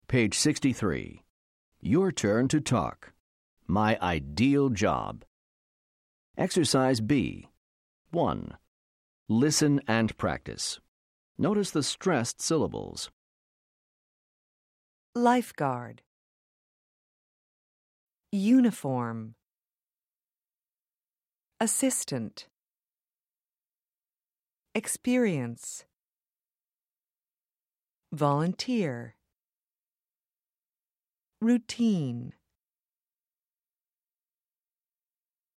American English
Class Audio CDs include natural conversational recordings for the listening tasks in each unit, pronunciation practice, and expansion units containing authentic student interviews.